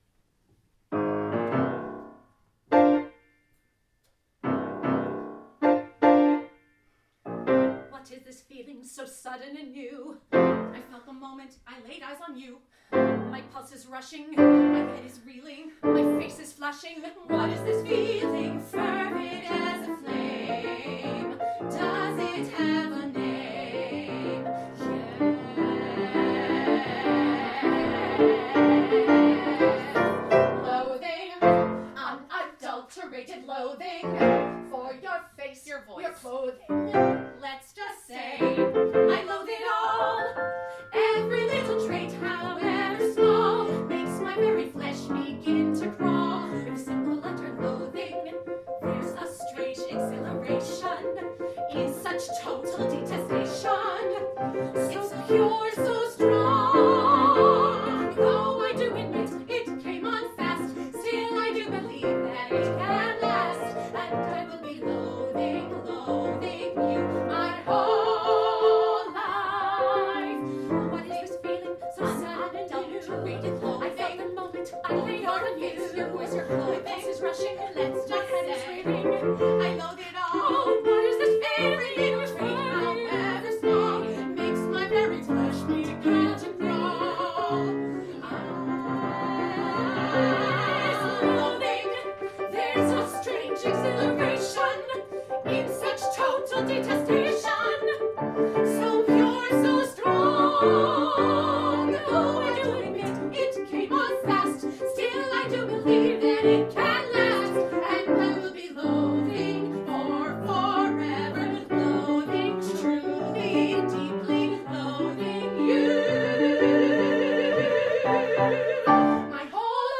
Here are audio recordings from our recital on April 5, 2014 in Marlborough, MA.
soprano
mezzo
piano